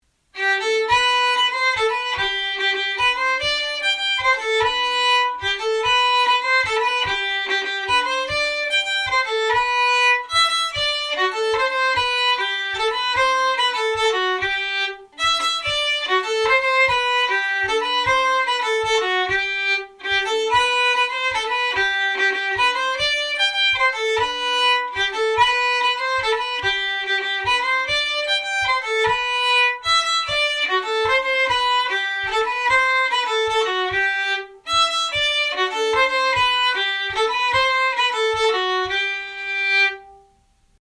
Polska-från-Rämmen.mp3